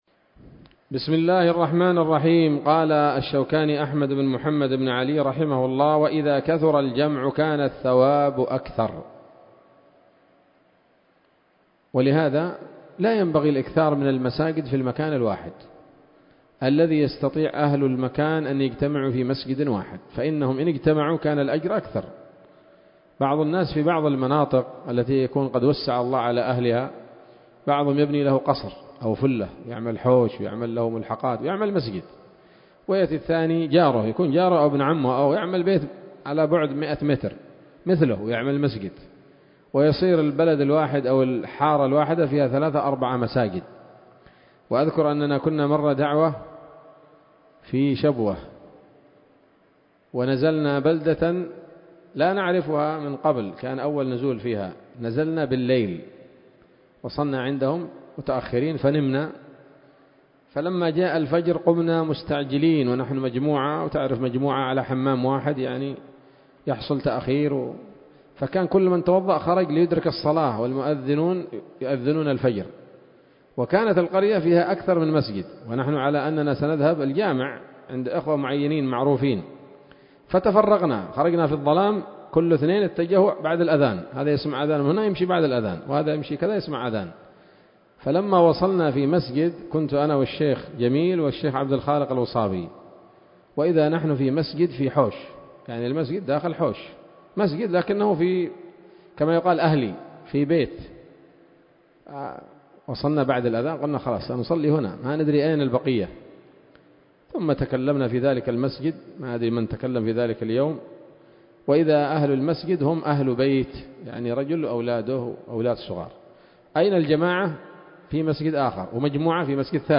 الدرس الخامس والعشرون من كتاب الصلاة من السموط الذهبية الحاوية للدرر البهية